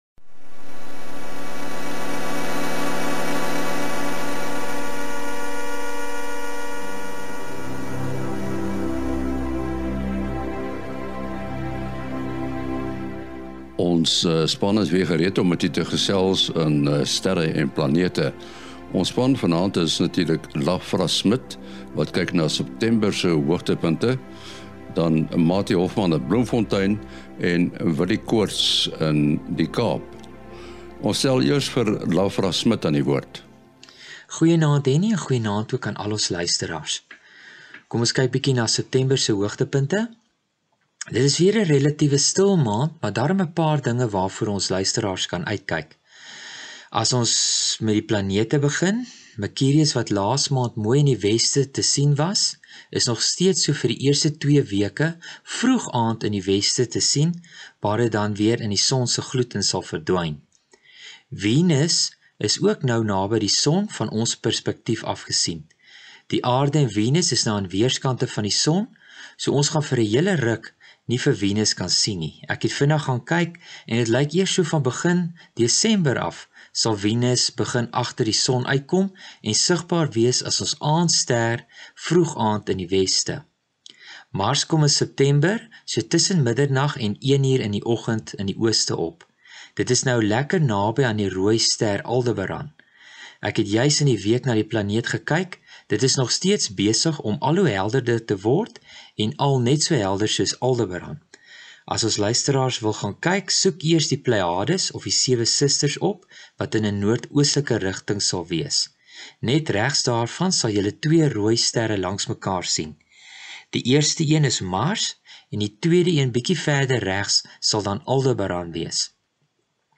Die kenwysie van Sterre en Planete is byvoorbeeld saamgestel uit klanke wat van verskillende sterre af kom. Ons luister na klank wat sy ontstaan het in ‘n reuse gravitasiekolk.